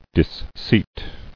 [dis·seat]